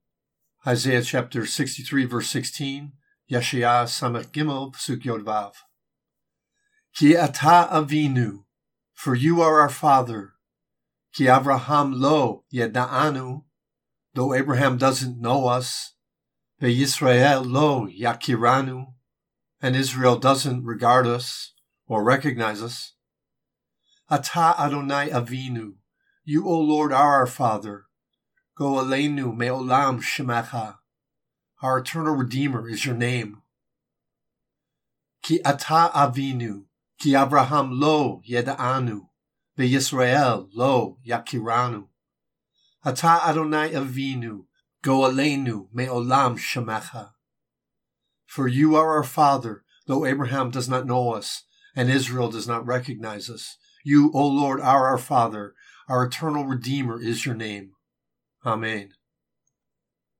Isaiah 63:13 reading (click):